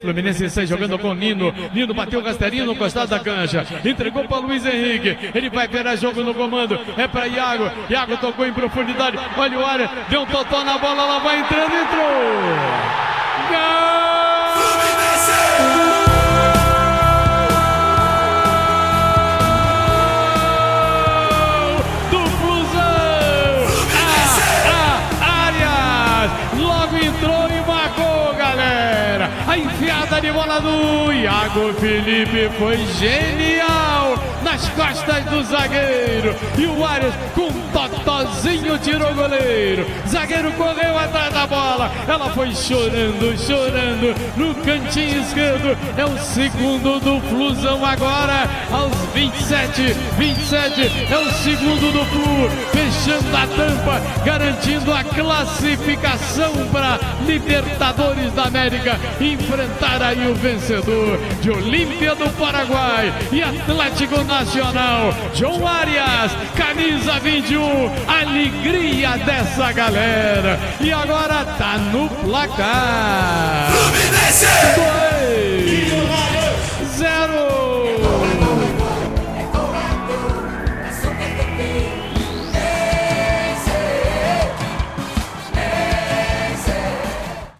Ouça os gols da vitória do Fluminense sobre o Millonarios pela Libertadores com a narração do Garotinho